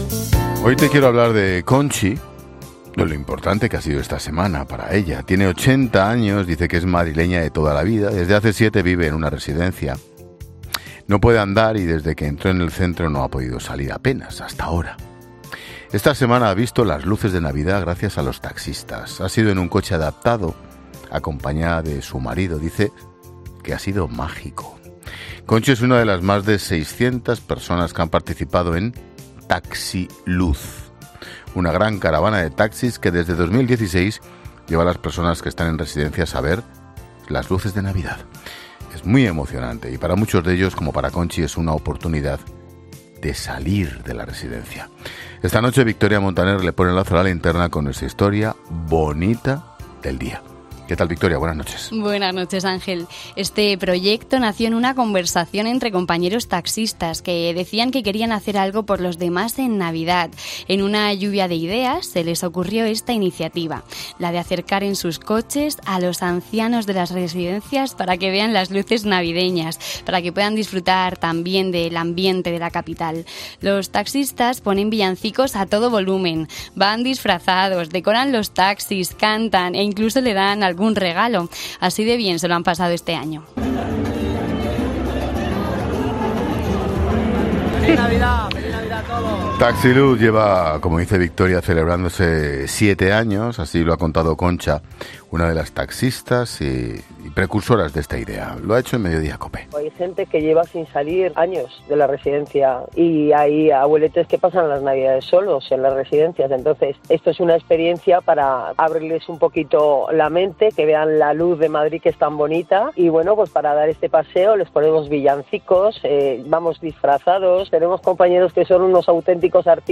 Ángel Expósito relata en La Linterna la iniciativa de taxistas españoles que están cambiando las vidas de las personas que se encuentran en residencias, durante la Navidad